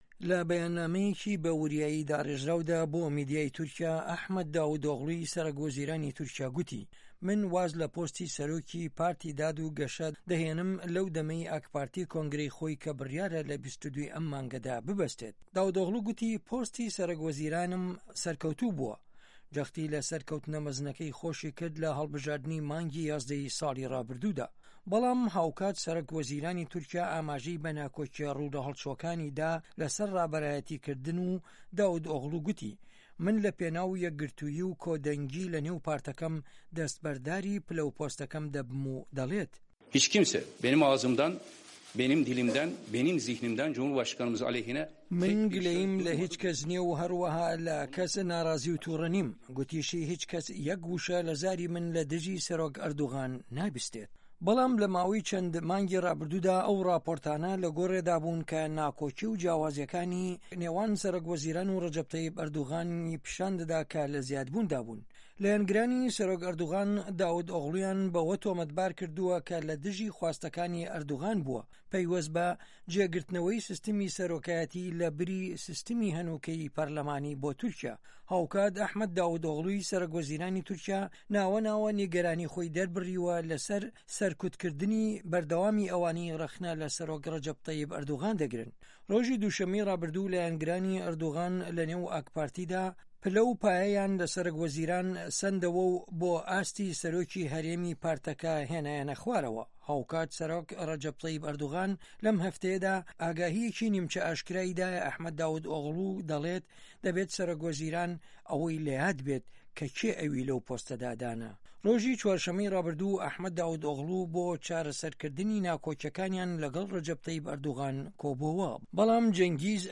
لە ئەستەمبول لەم فایلە دەنگیەدا دەبیستن